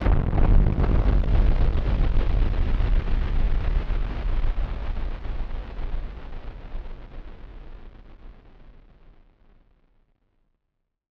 BF_DrumBombC-02.wav